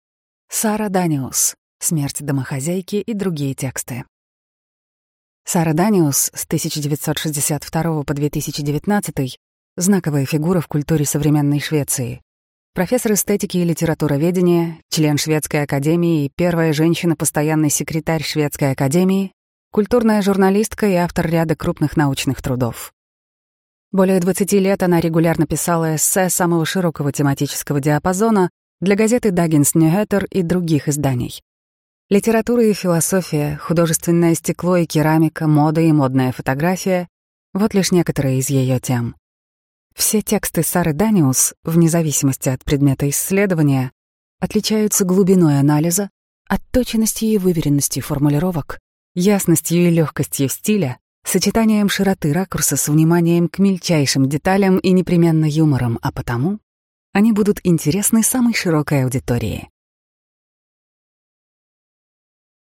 Аудиокнига Смерть домохозяйки и другие тексты | Библиотека аудиокниг